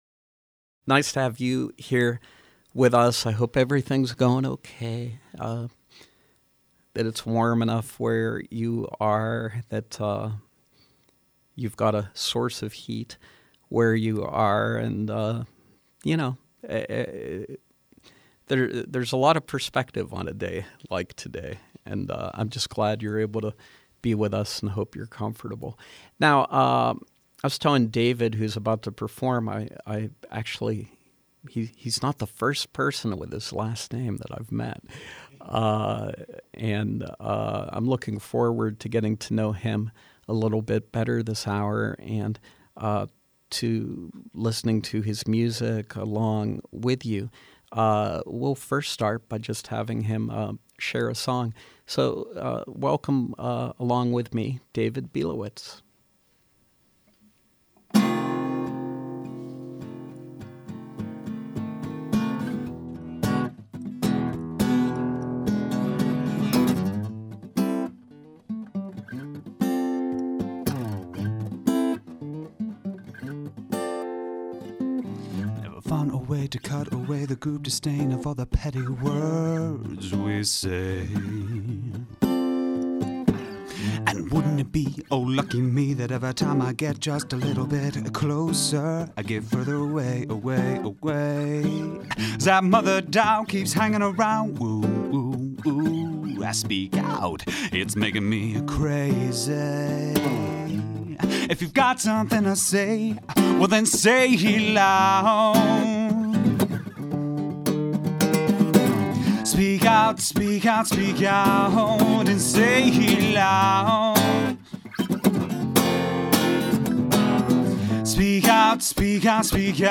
Pittsburgh-based singer/songwriter